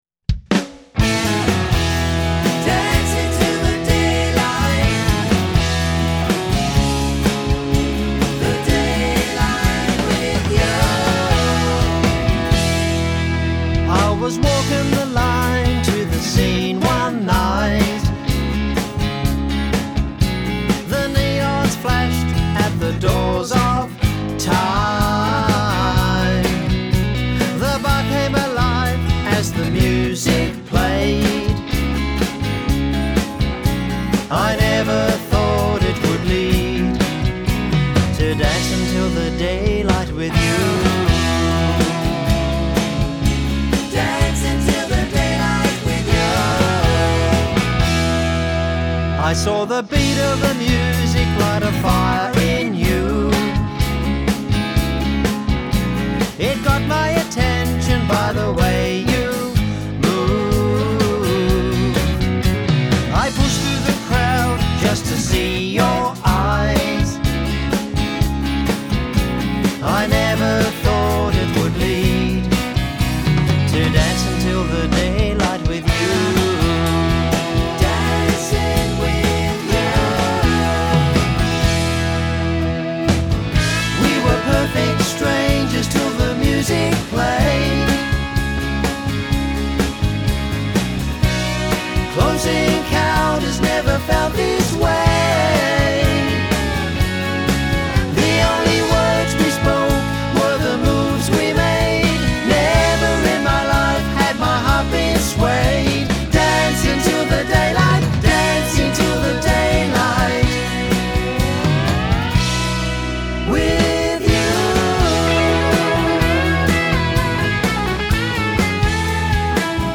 Drums for many an Australian artist